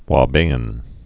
(wä-bāĭn)